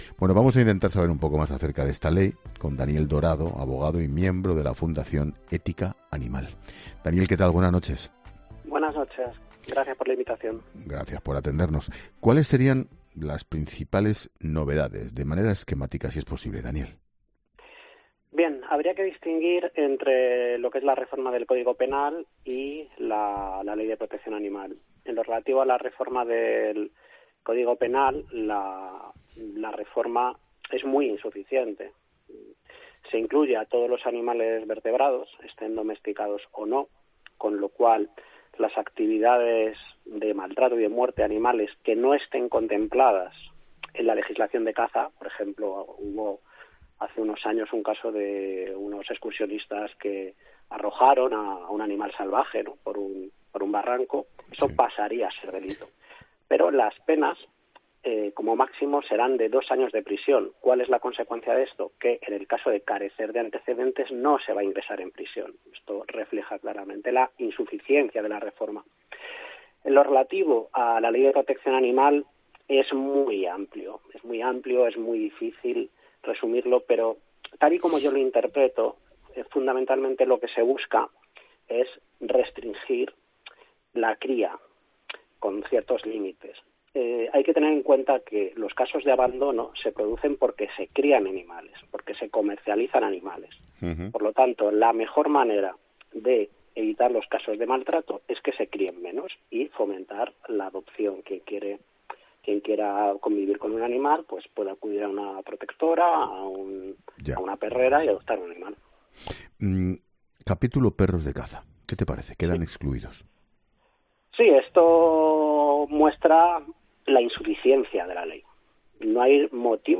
Un abogado experto en ética animal analiza las claves de la Ley de Bienestar Animal: "Es muy insuficiente"